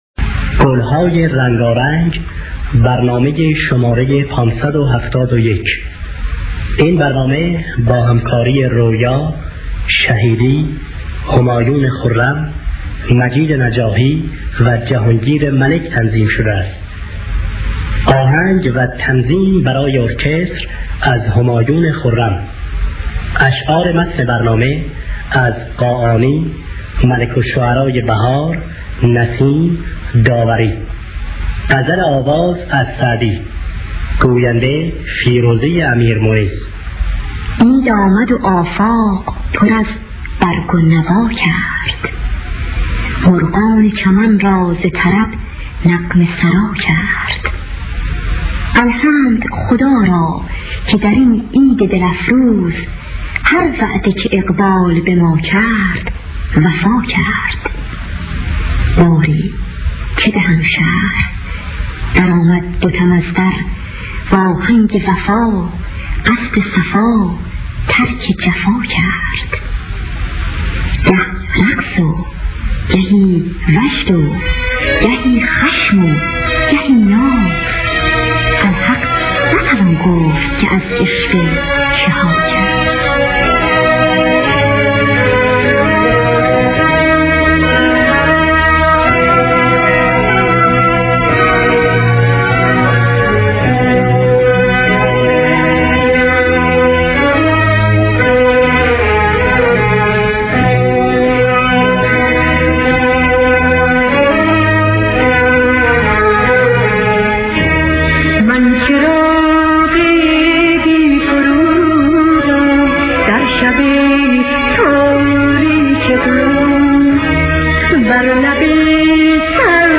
دانلود گلهای رنگارنگ ۵۷۱ با صدای عبدالوهاب شهیدی، رویا در دستگاه بیات اصفهان.
دانلود گلهای رنگارنگ ۵۷۱ - آرشیو کامل برنامه‌های رادیو ایران